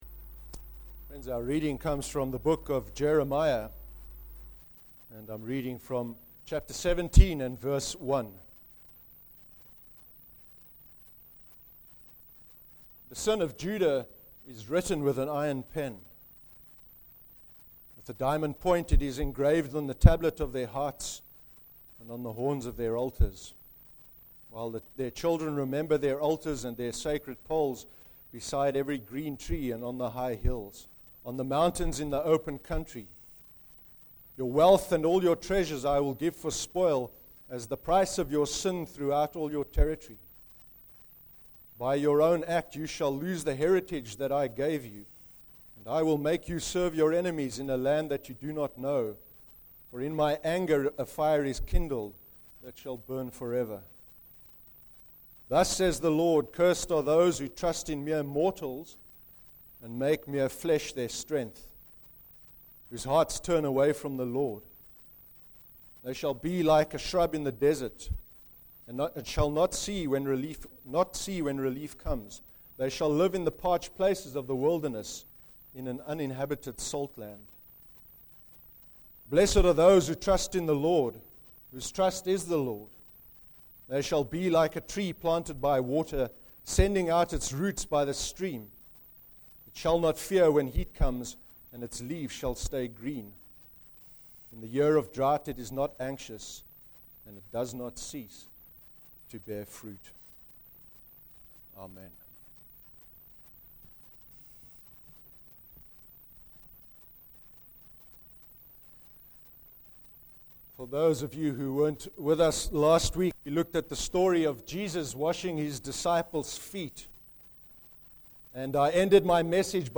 14/07/13 sermon – Jeremiah 17:1-8